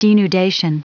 Prononciation du mot denudation en anglais (fichier audio)
Prononciation du mot : denudation